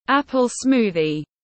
Sinh tố táo tiếng anh gọi là apple smoothie, phiên âm tiếng anh đọc là /ˈæp.əl ˈsmuː.ði/
Apple smoothie /ˈæp.əl ˈsmuː.ði/